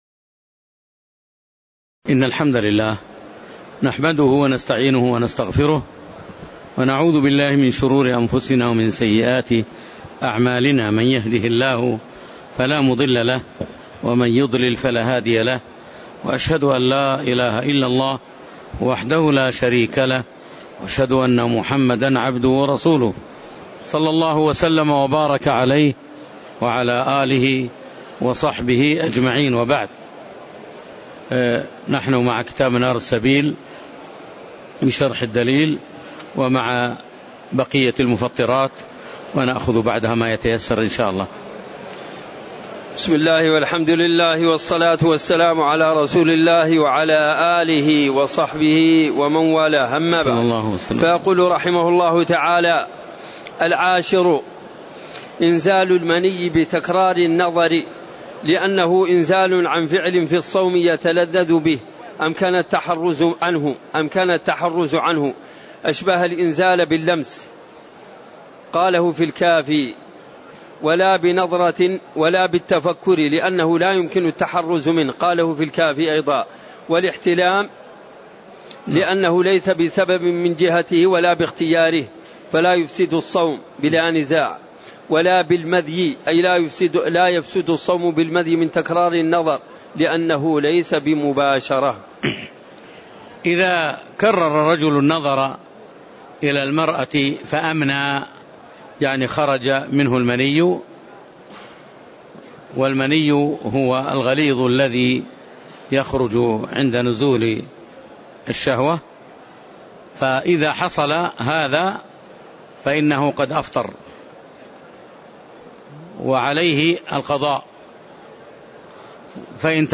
تاريخ النشر ١٠ رمضان ١٤٣٨ هـ المكان: المسجد النبوي الشيخ